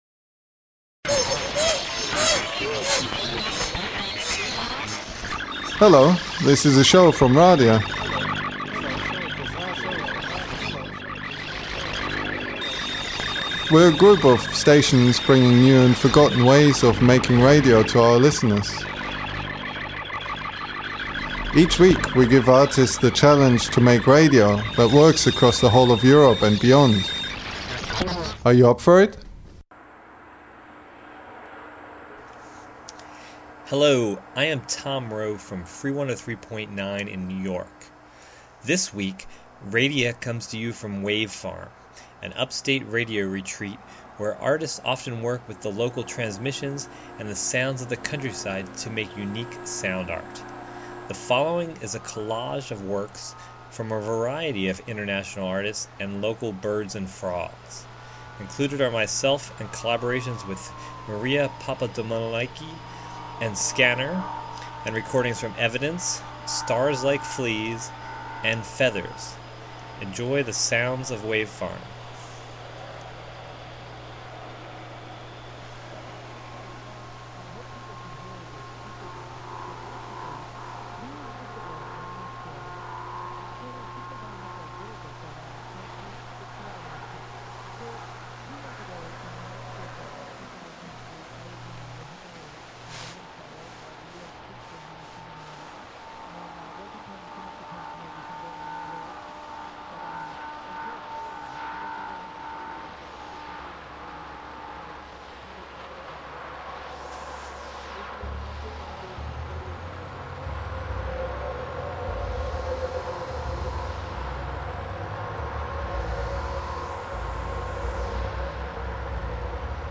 A collage of sounds from Wave Farm and works created by artists at Wave Farm